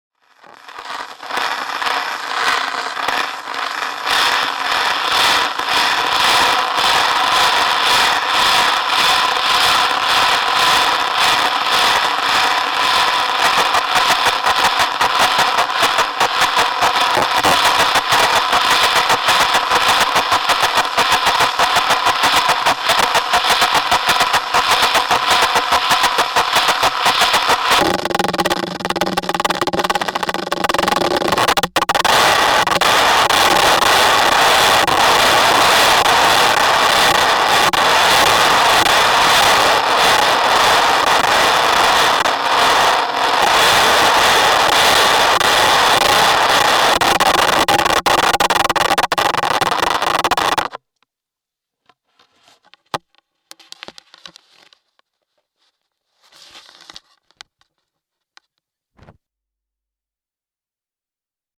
I taped a contact mic to the end of a bamboo cane and dragged it along the floor at the same time, picking up the variation in textures.
One minute audio extract, dragging the stick over the anti-slip strips at the exit of the mall:
mall-floor-survey-extract.mp3